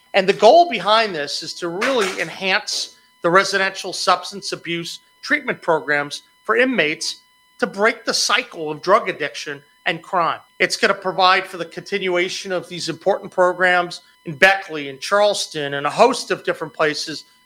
The Governor made the announcement in Parkersburg as he continues a travel tour of the state. Morrisey said fighting the substance abuse epidemic requires a holistic approach, including prevention, intervention, and enforcement…